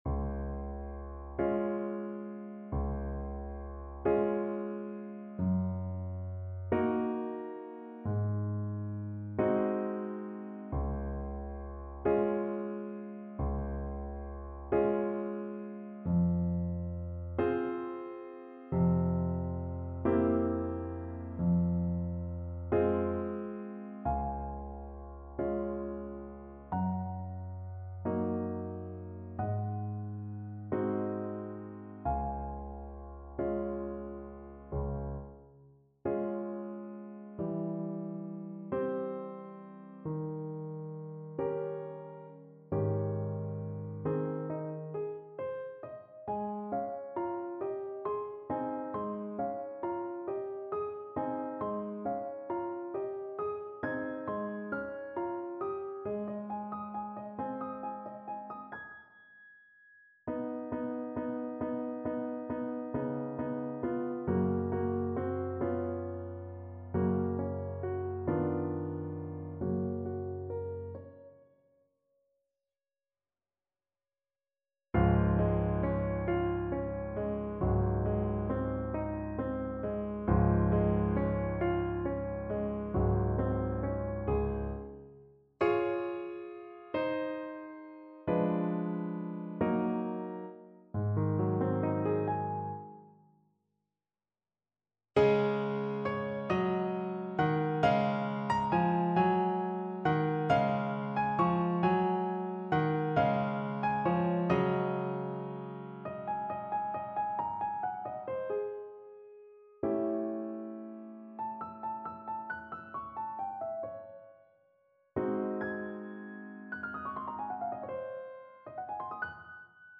Andante .=45
6/8 (View more 6/8 Music)
Classical (View more Classical Mezzo Soprano Voice Music)